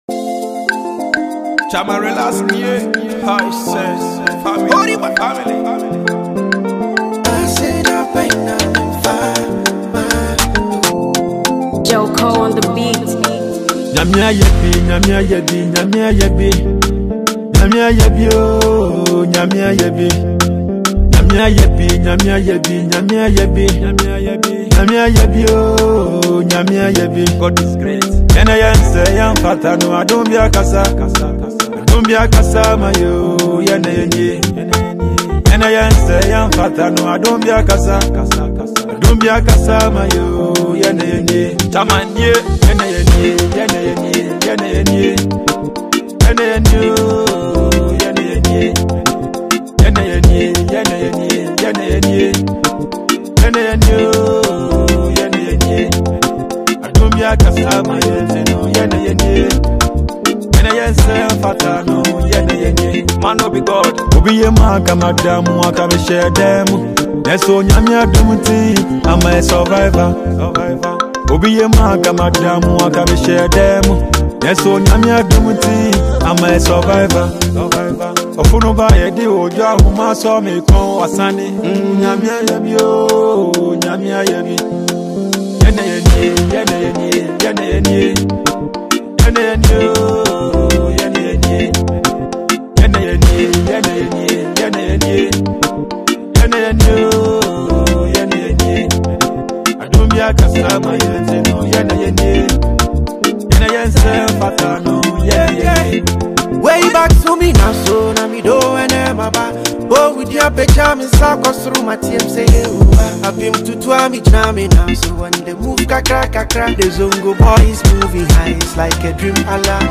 rapper
inspirational new single